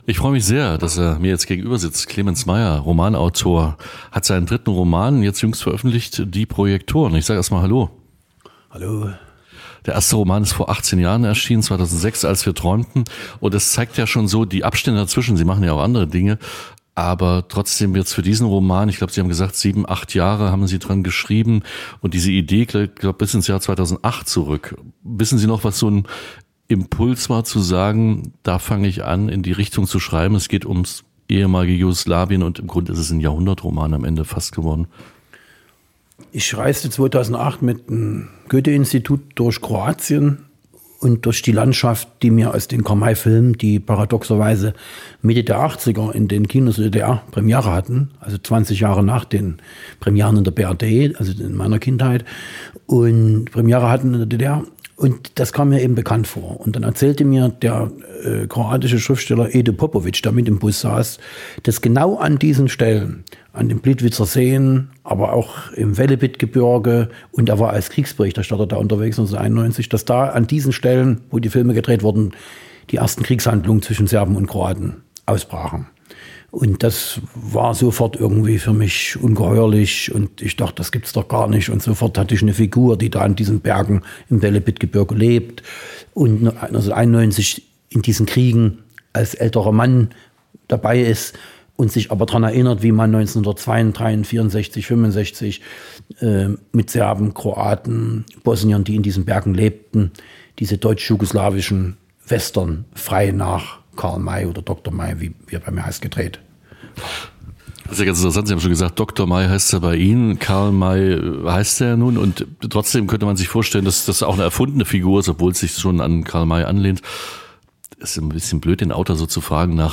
INTERVIEW Clemens Meyer.mp3